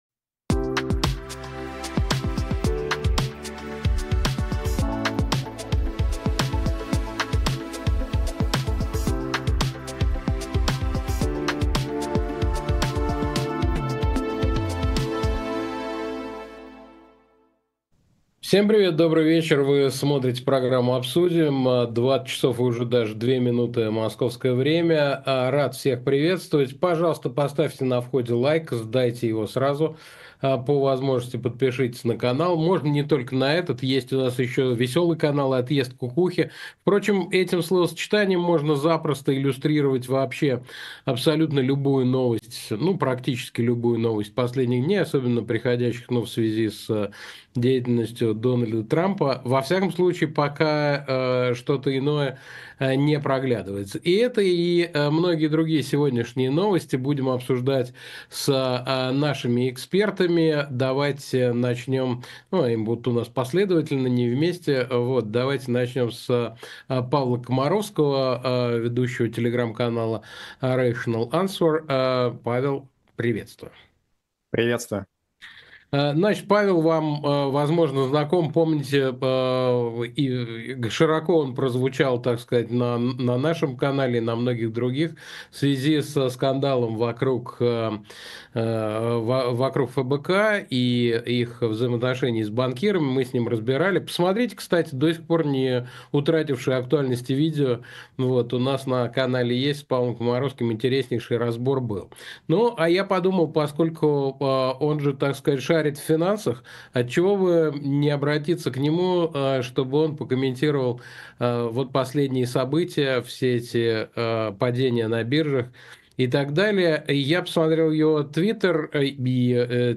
Эфир ведёт Александр Плющев
В программе “Обсудим” мы в прямом эфире говорим о самых важных событиях с нашими гостями.